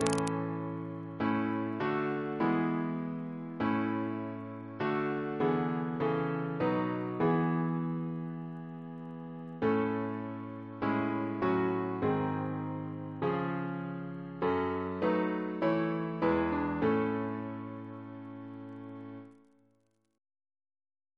Double chant in A Composer: Henry Edward Dibdin (1813-1866) Reference psalters: ACP: 336; H1982: S211; PP/SNCB: 94